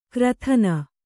♪ krathana